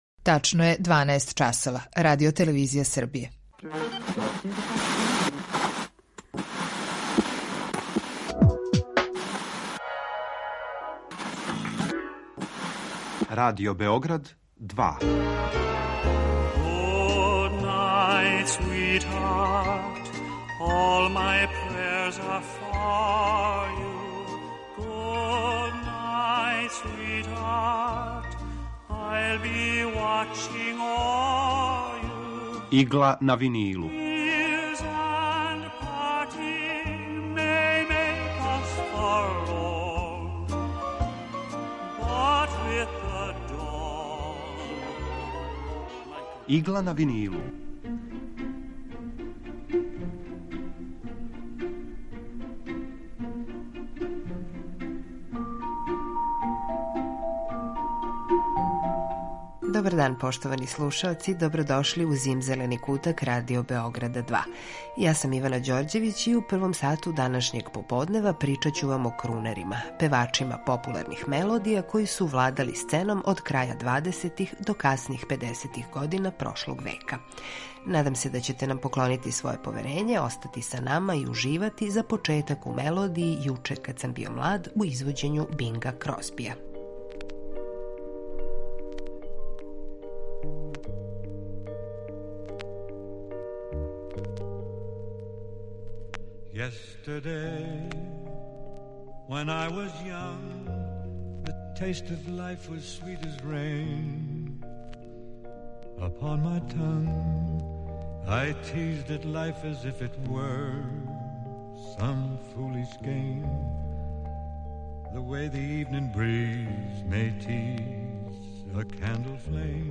У данашњој Игли на винилу подсетићемо вас на неке од најпопуларнијих певача- крунера.
У Игли на винилу представљамо одабране композиције евергрин музике од краја 40-их до краја 70-их година 20. века. Свака емисија садржи кроки композитора / извођача и рубрику Два лица једног хита (две верзије исте композиције)